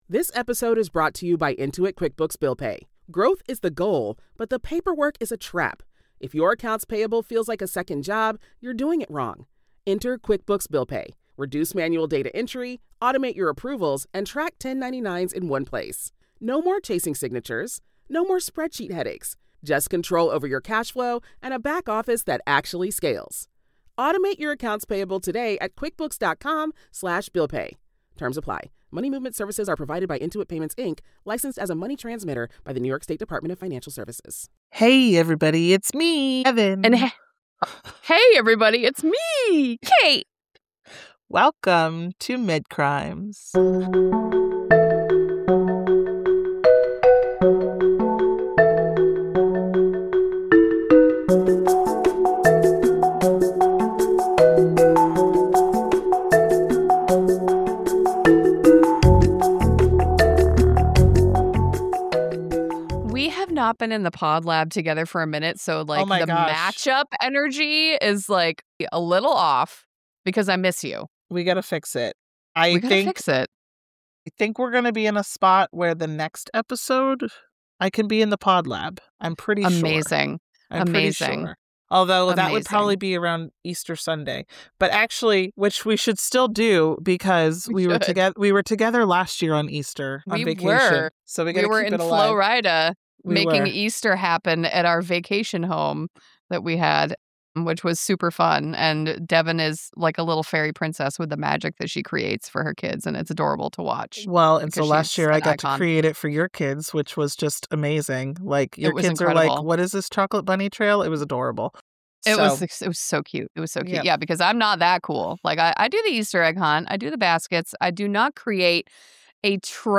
The hosts are two best friends who are chatting about true crime cases in the medical field.